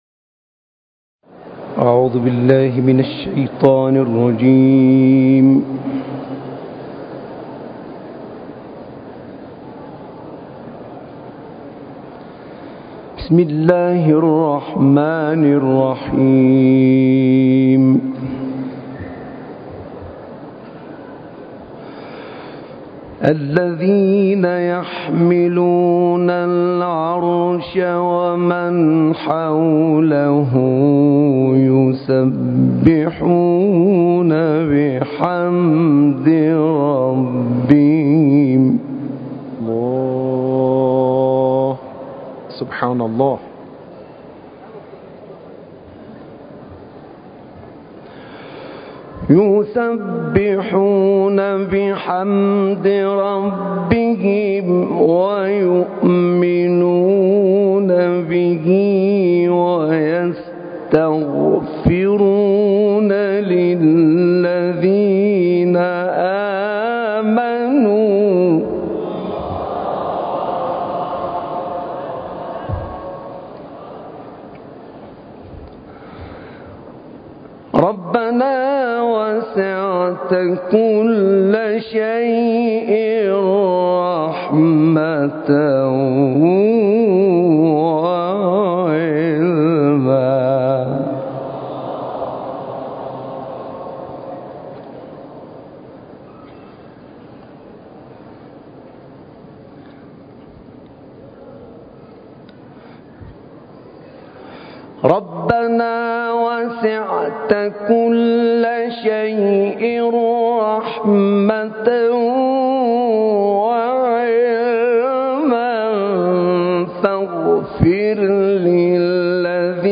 خاطره‌ای از تلاوت «احمد نعینع» در سالن اجلاس سران + تلاوت